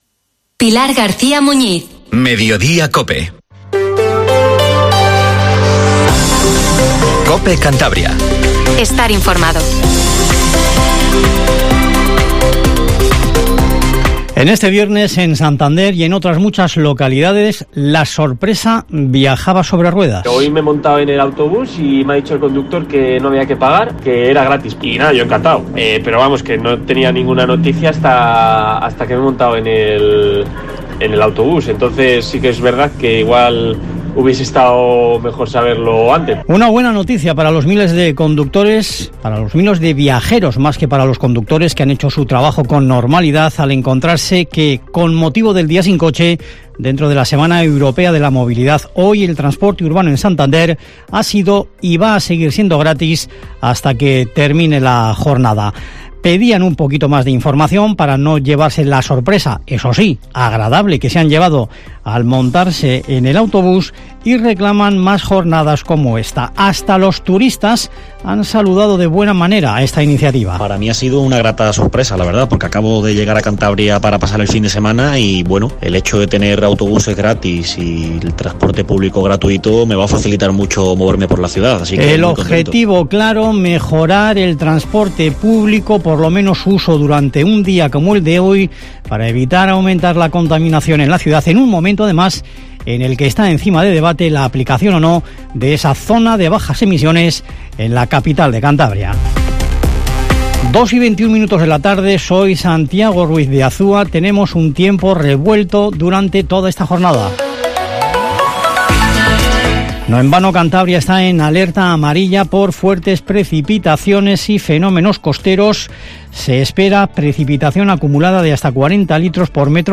Informativo MEDIODIA en COPE CANTABRIA 14:20